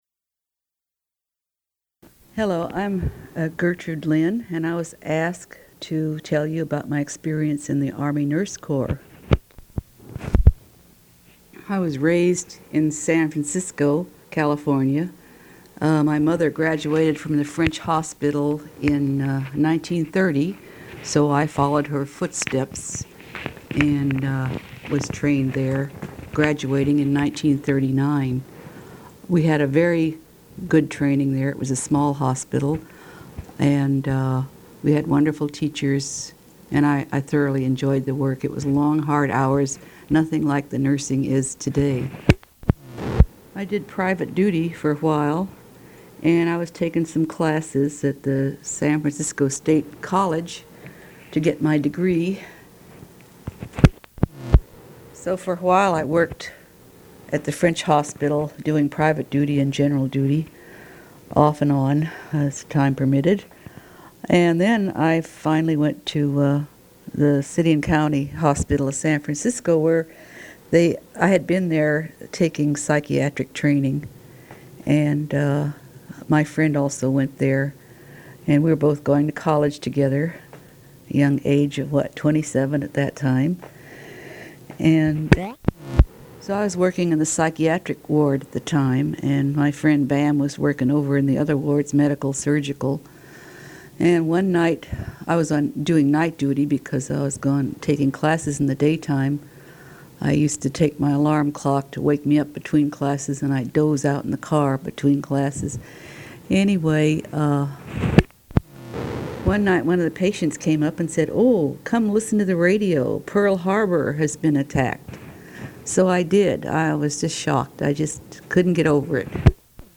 Women's Overseas Service League Oral History Project